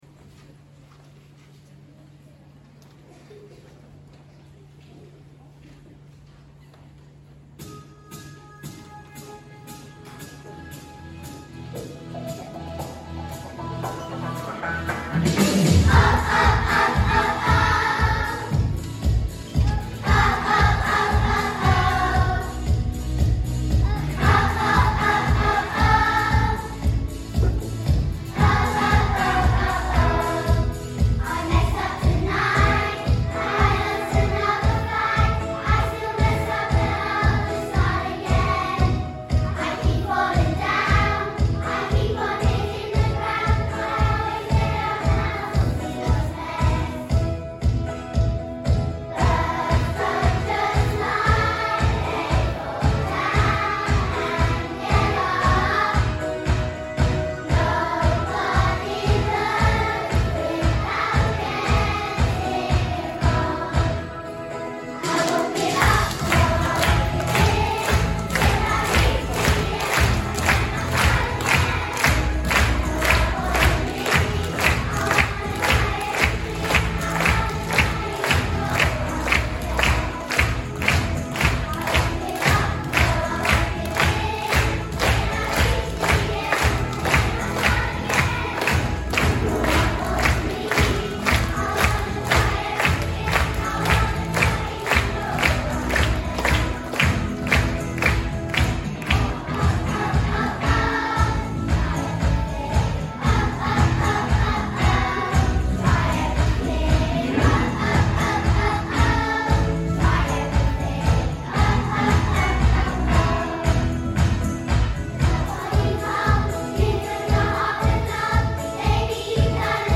Try Everything | Y2/3 Choir